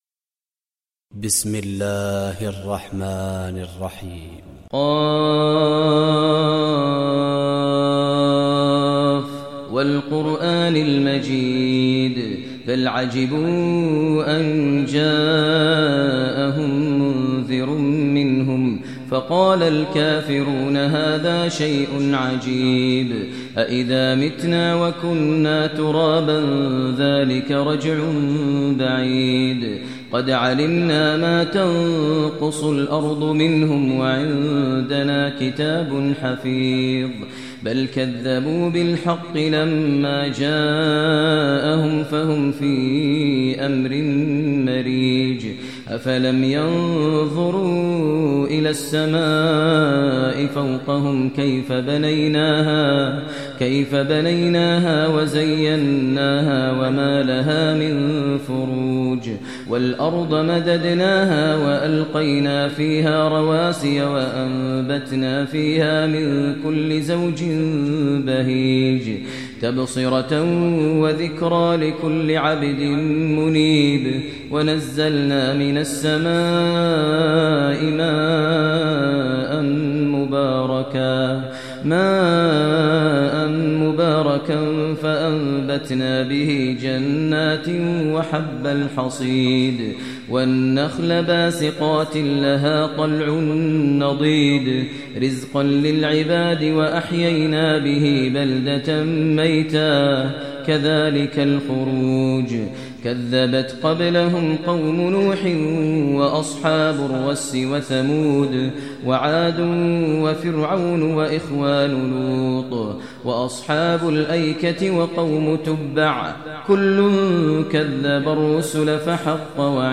Surah Qaf Recitation by Sheikh Maher al Mueaqly
Surah Qaf is 50 chapter of Holy Quran. Listen online mp3 tilawat / recitation in Arabic in the voice of Sheikh Maher al Mueaqly.